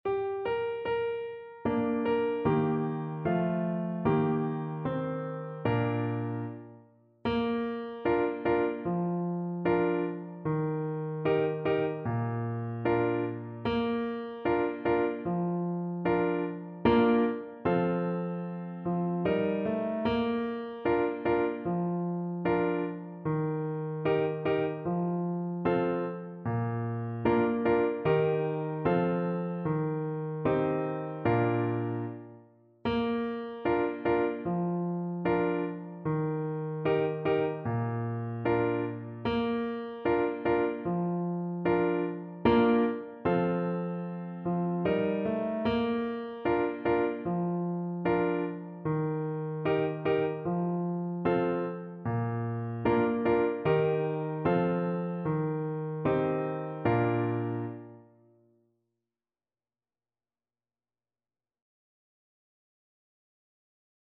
4/4 (View more 4/4 Music)
Moderato